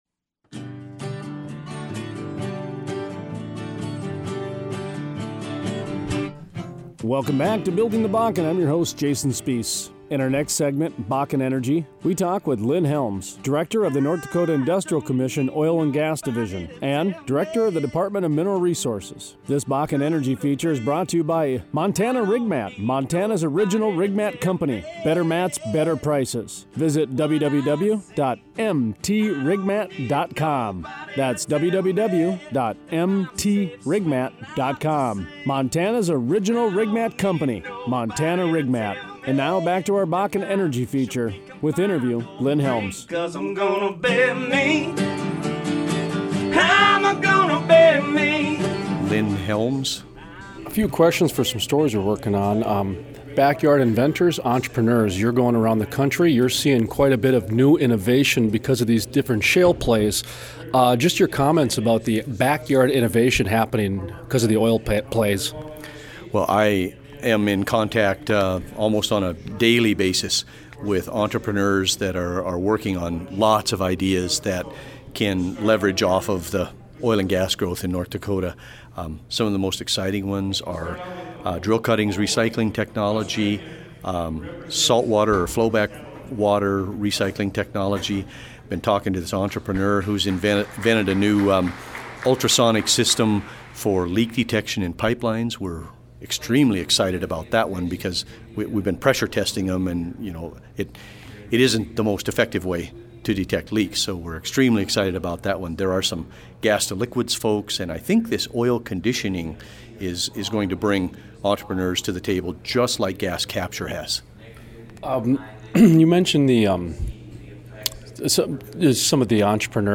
Interview: Lynn Helms, Director, Department Mineral Resources